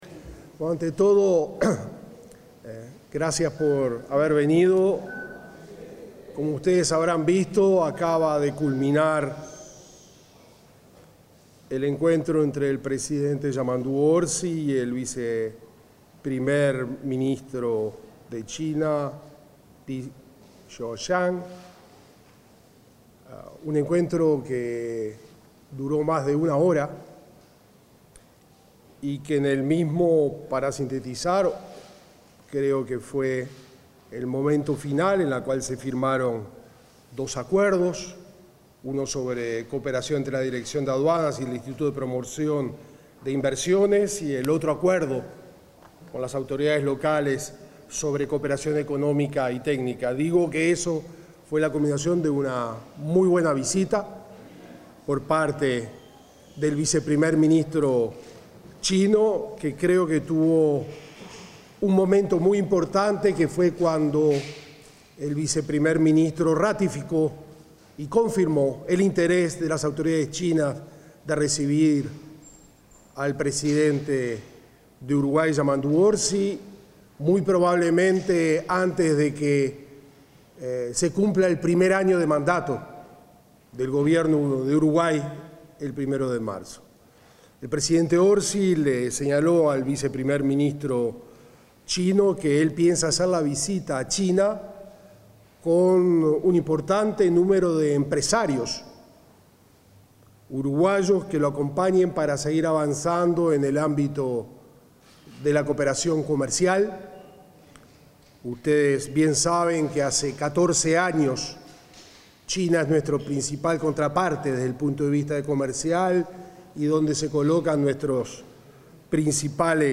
Declaraciones del canciller Mario Lubetkin